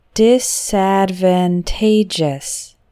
Medium: